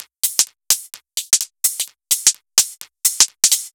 Index of /musicradar/uk-garage-samples/128bpm Lines n Loops/Beats
GA_BeatFiltC128-01.wav